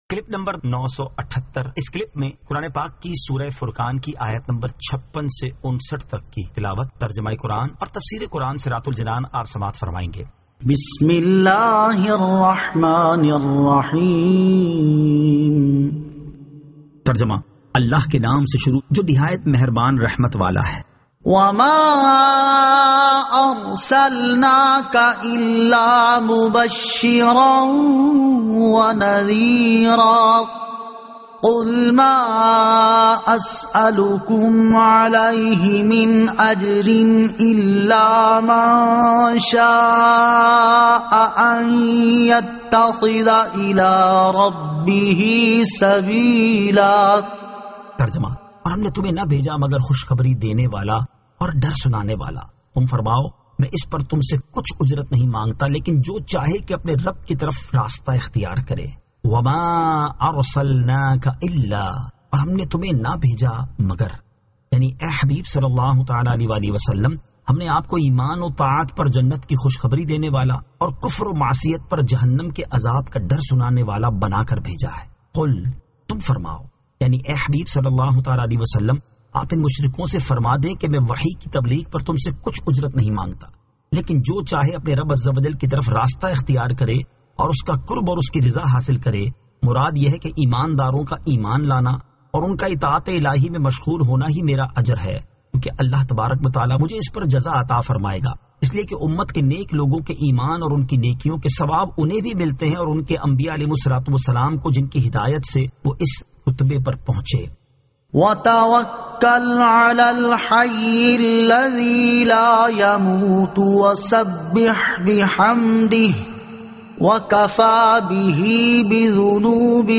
Surah Al-Furqan 56 To 59 Tilawat , Tarjama , Tafseer